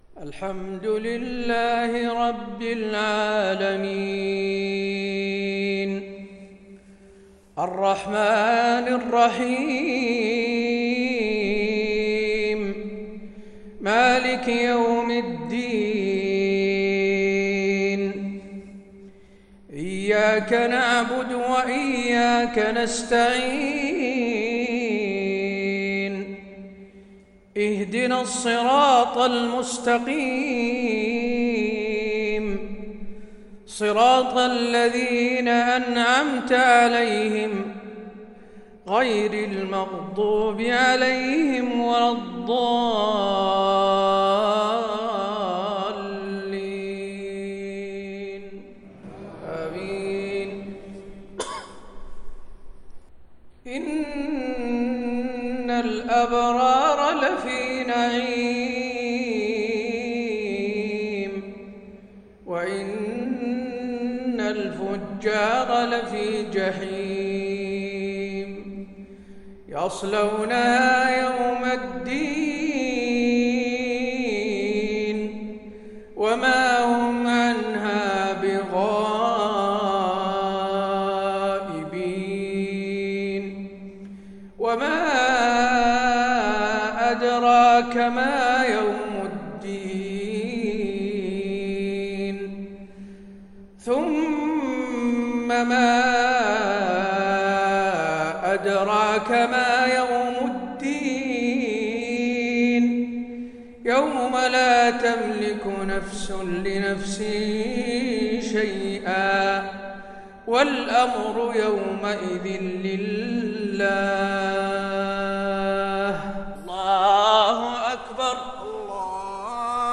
صلاة المغرب للشيخ حسين آل الشيخ 18 ربيع الأول 1442 هـ
تِلَاوَات الْحَرَمَيْن .